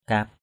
/ka:p/ 1.